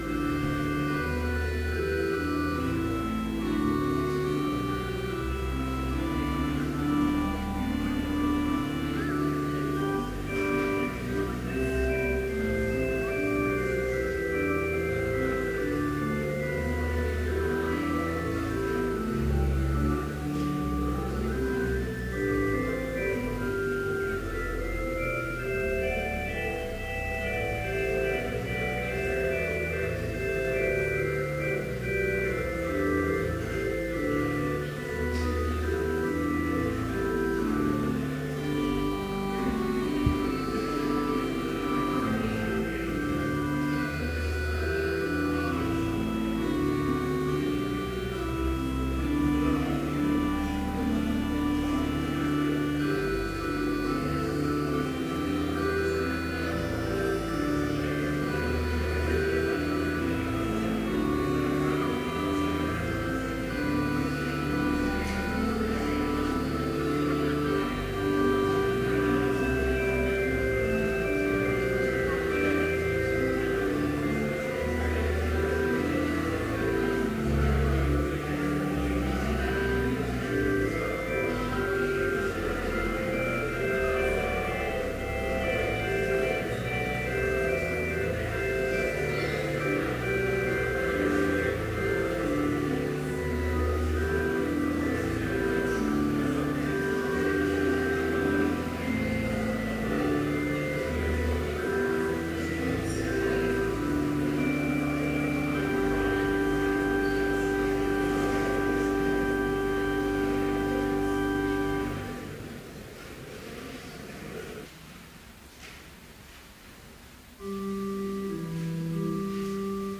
Complete service audio for Chapel - January 22, 2014